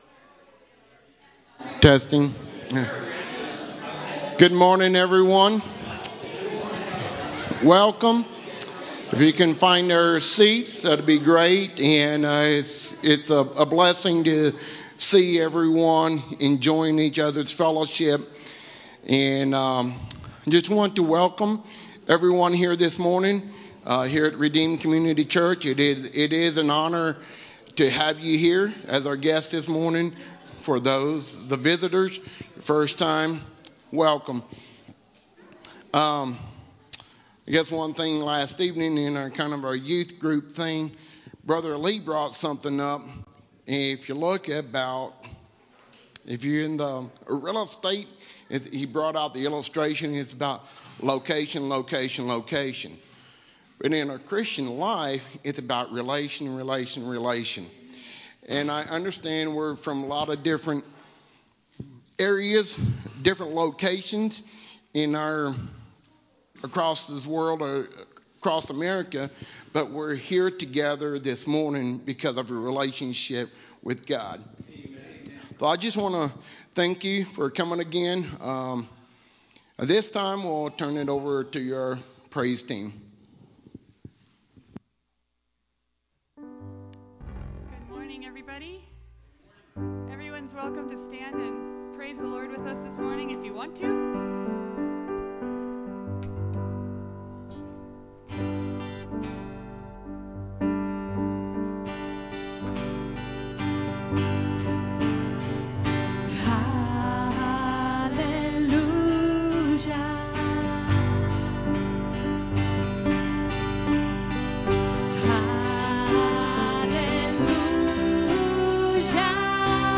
Live Broadcast-Oct 27 2024